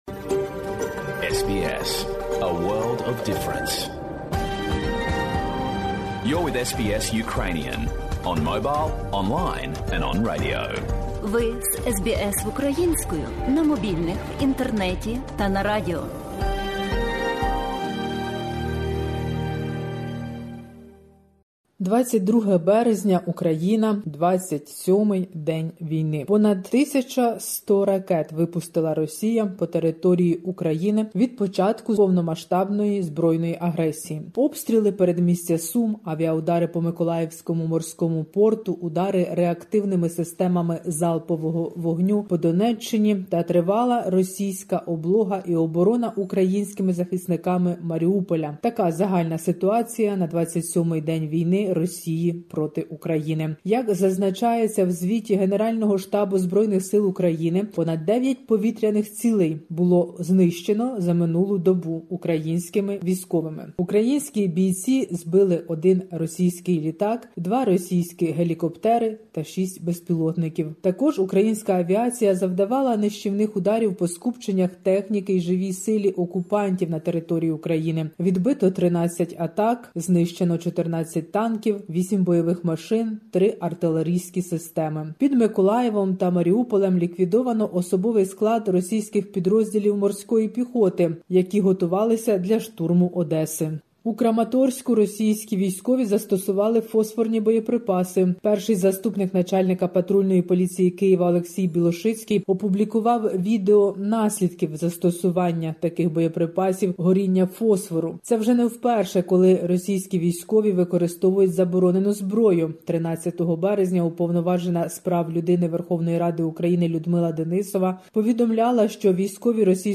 Добірка новин для радіослухачів SBS Ukrainian. Війна - з початку військових дій понад 1100 ракет випустили російські війська на українські міста і села. Тривоги на Волині через можливі конфлікти з армією Білорусі. Мітинґ українців в окупованому Херсоні.